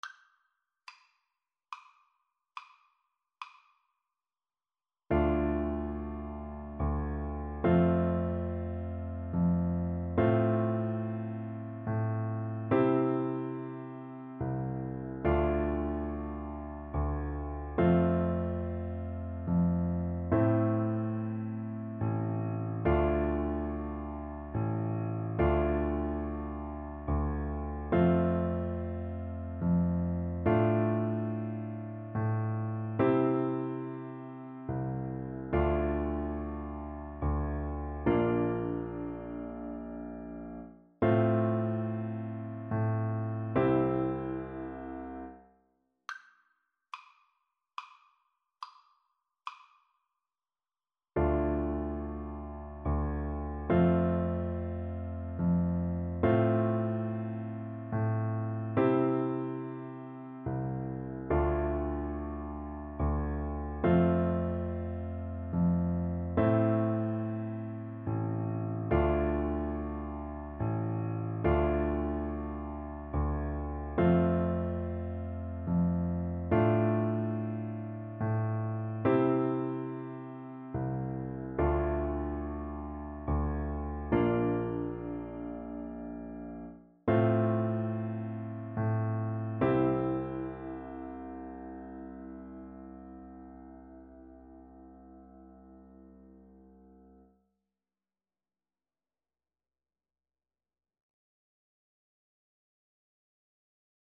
6/8 (View more 6/8 Music)